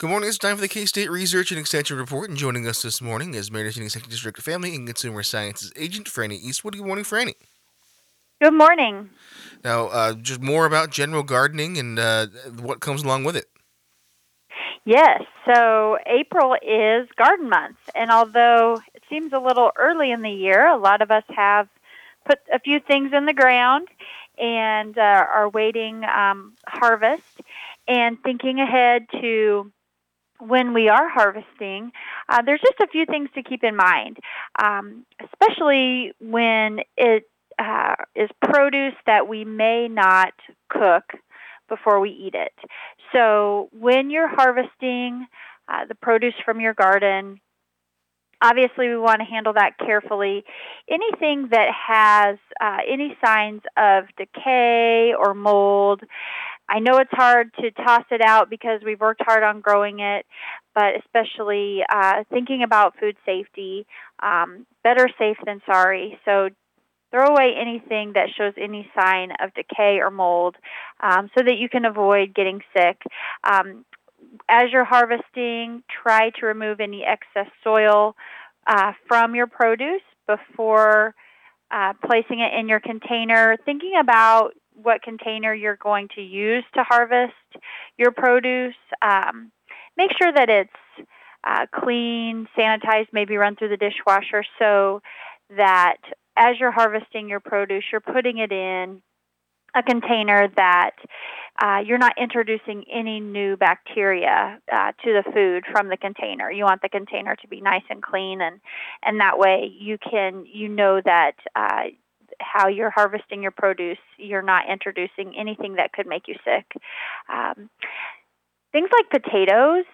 KOFO Interviews 2025